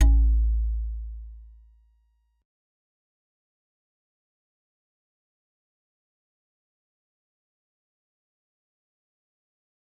G_Musicbox-A1-pp.wav